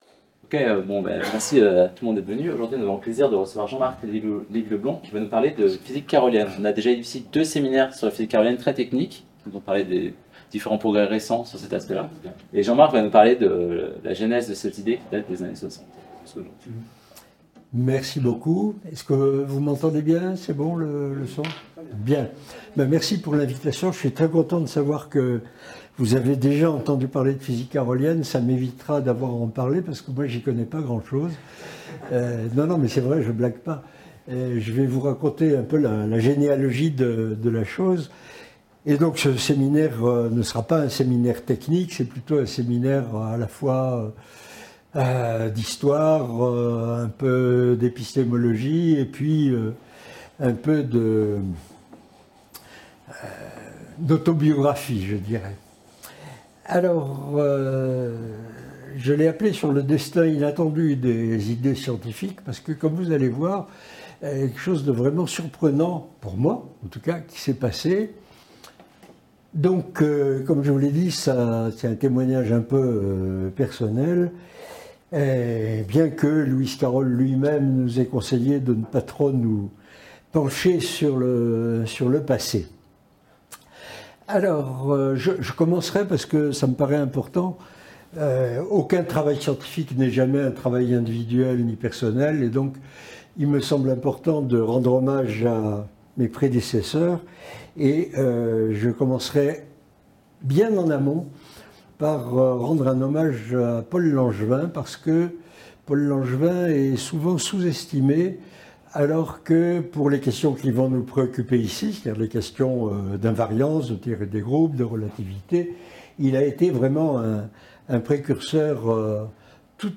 Ce séminaire en français, de Jean-Marc Lévy-Leblond (professeur émérite à l'Université de la Côte d'Azur) a été enregistré lundi 21 octobre 2024 à l'IAP.